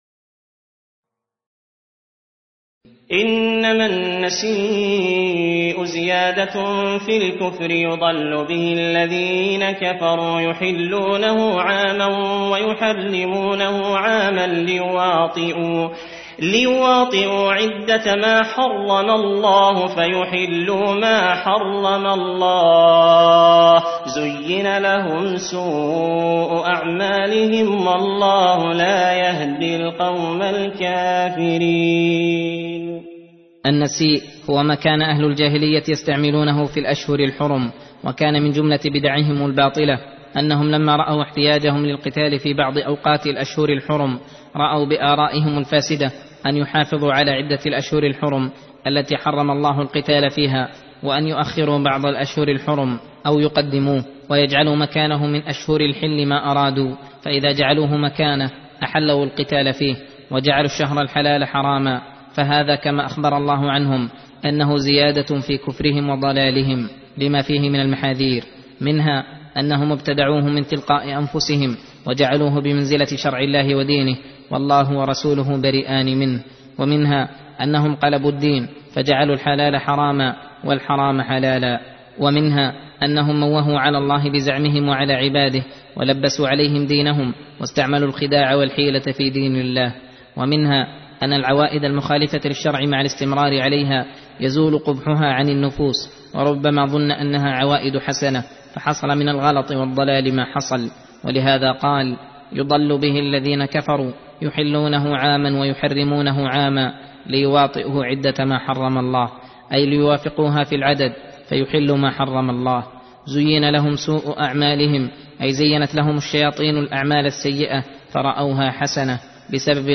درس (19) : تفسير سورة التوبة (37-51)